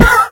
horse_hit1.ogg